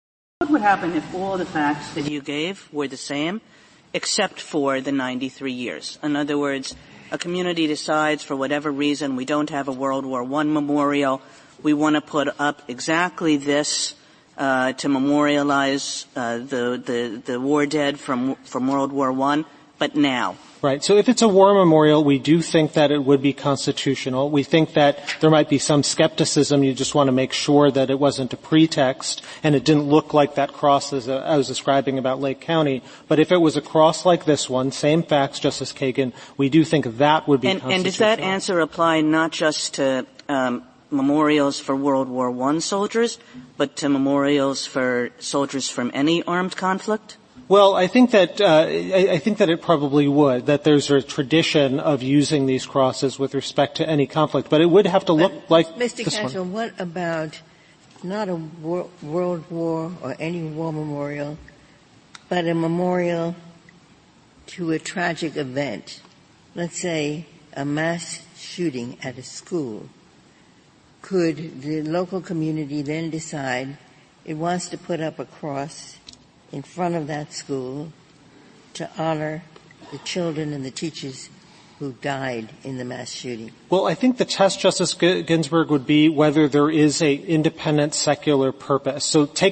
Excerpt From Oral Argument in American Legion v. American Humanist Association